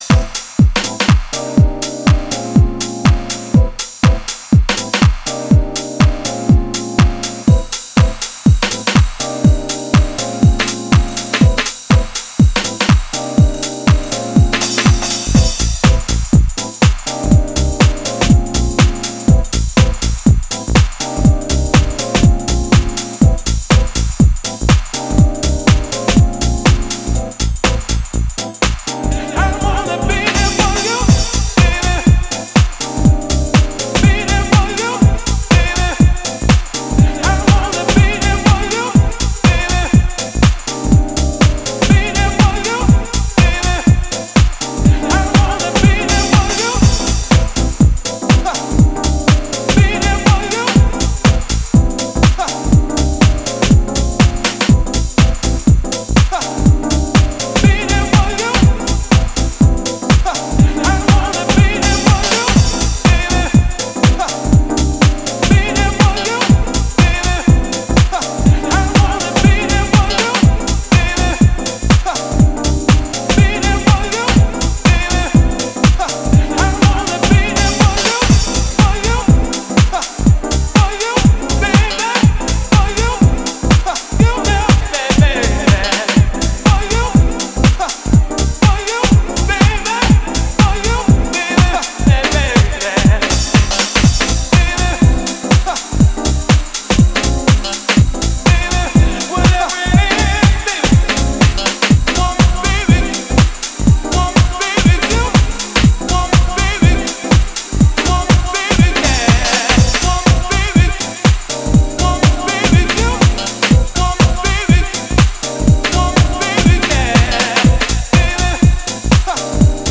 絢爛なピアノとソウルフルなヴォイス・サンプルがウォームでオーセンティックな魅力を放つディープ・ハウス